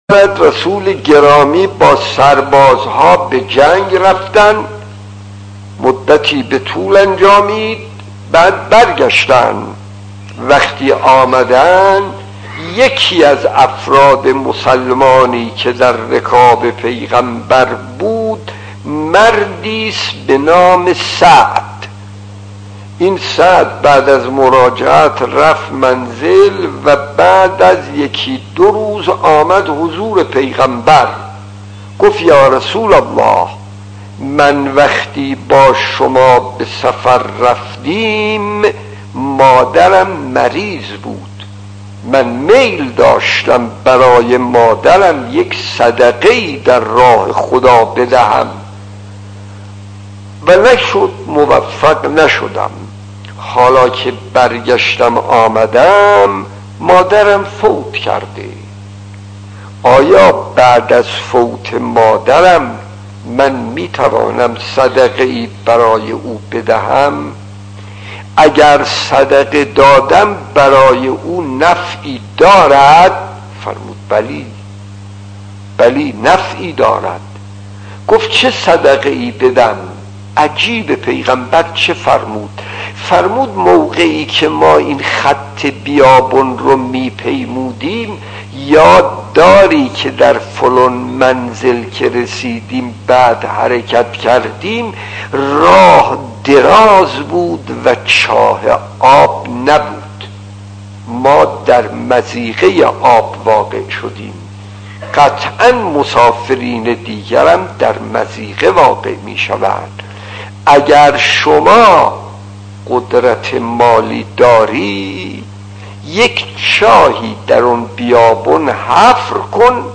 داستان 39 : صدقه خطیب: استاد فلسفی مدت زمان: 00:02:30